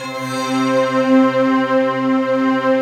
SI1 CHIME08L.wav